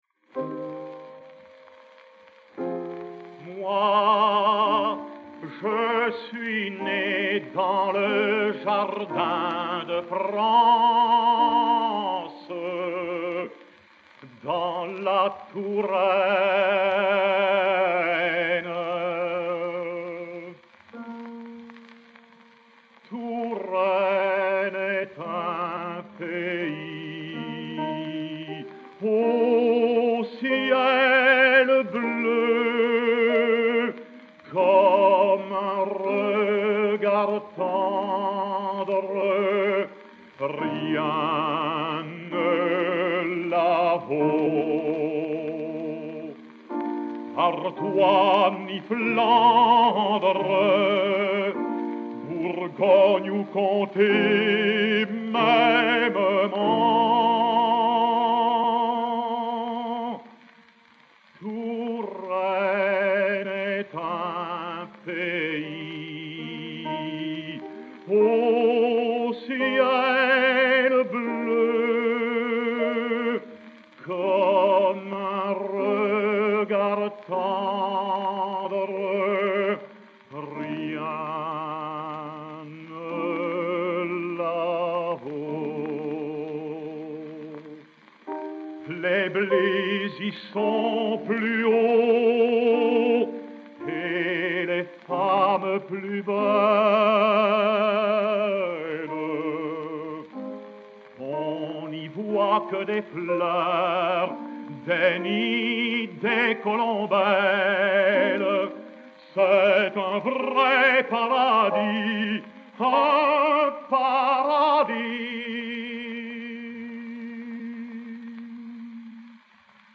Vanni-Marcoux (Panurge) et Piero Coppola au piano
BF 3316-2, enr. à Paris le 28 mai 1930